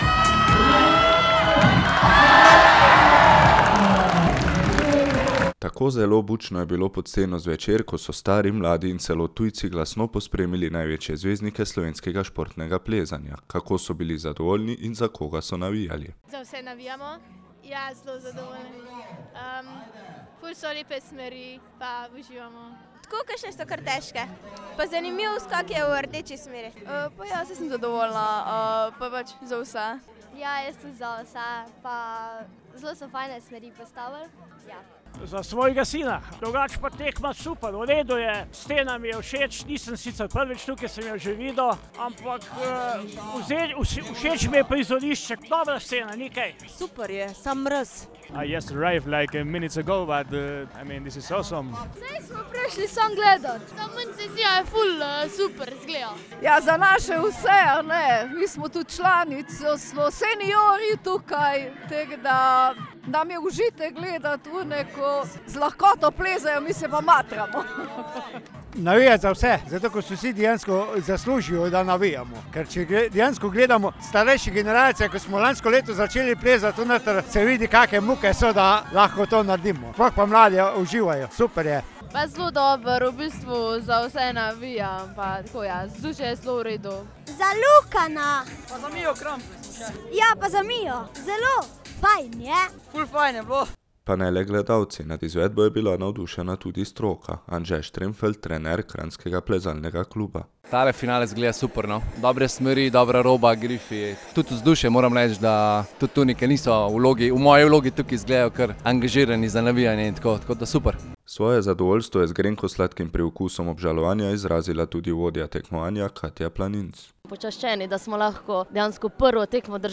Reportaža s članskega finala državnega prvenstva v športnem plezanju